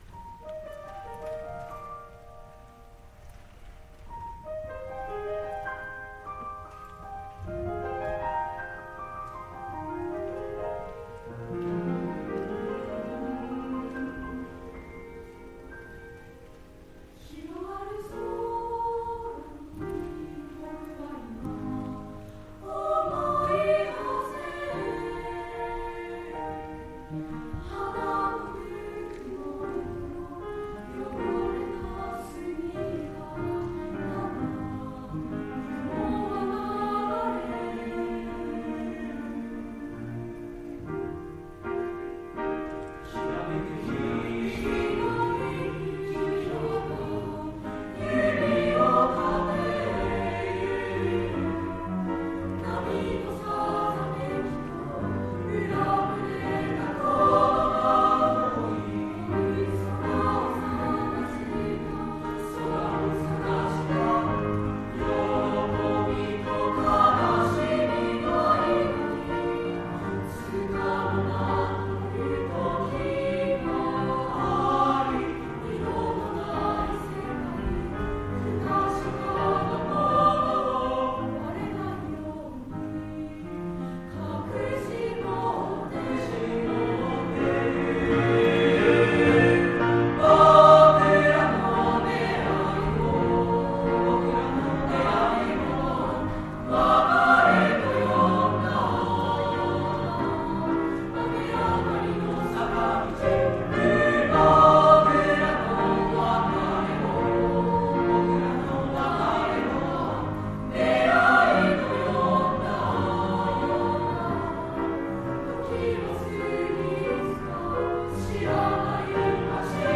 令和元年度 文化祭／合唱コンクールの歌声 【３年１・６組】
合唱コンクールでの３年１・６組の合唱を掲載しました。